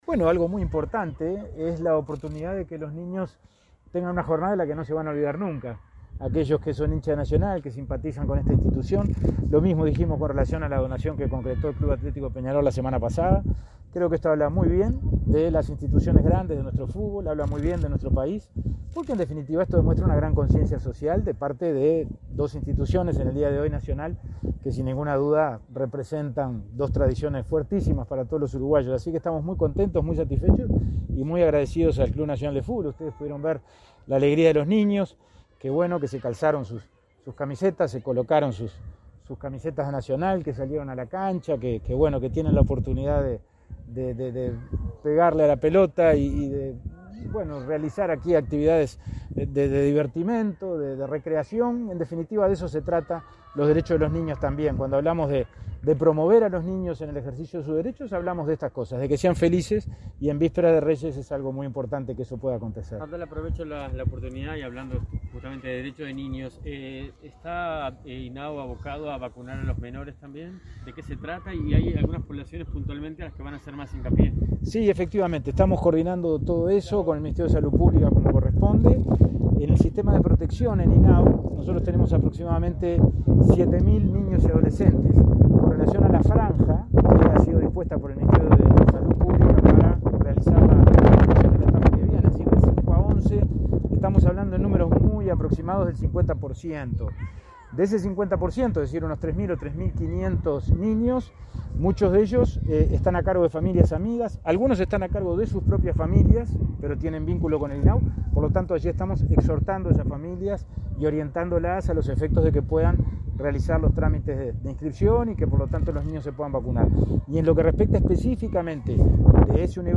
Declaraciones a la prensa del presidente de INAU
El presidente de INAU, Pablo Abdala, dialogó con la prensa sobre la donación que recibió la institución por parte del Club Nacional de Football.